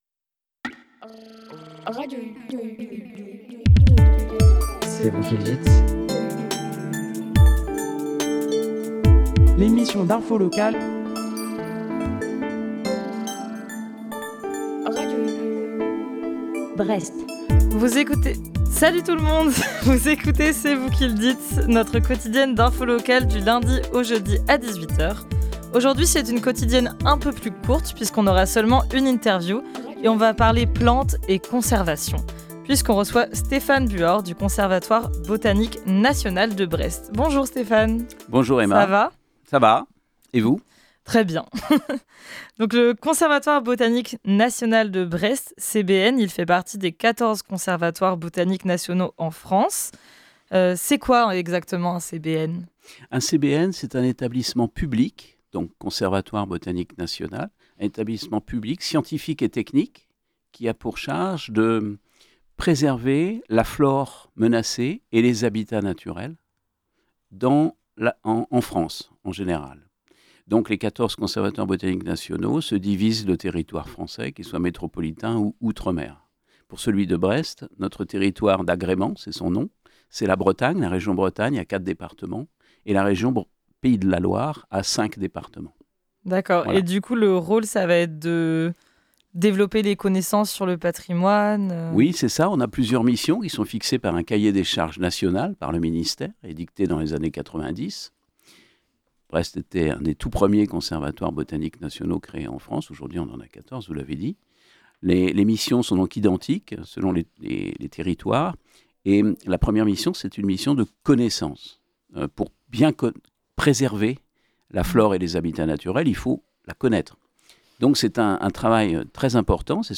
Aujourd’hui, c’est une quotidienne plus courte puisqu’on aura qu’un invité.